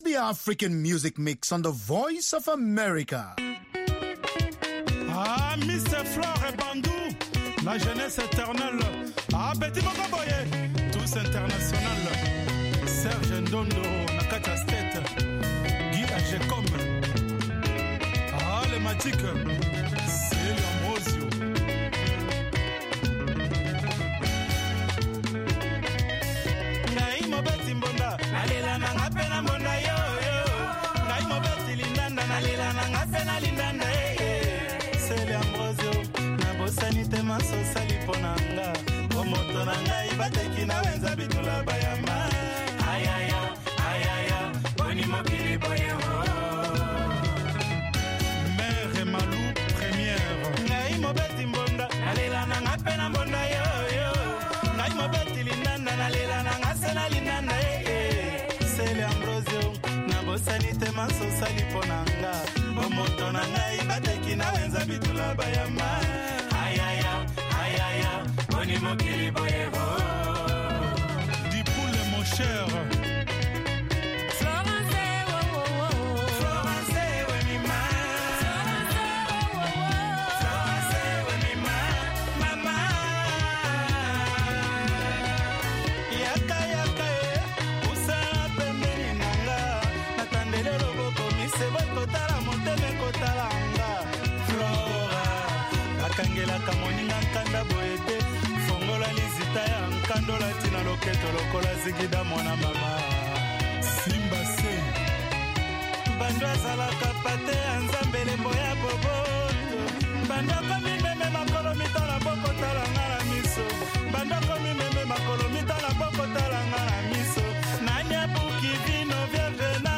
from N’dombolo to Benga to African Hip Hop
pan-African music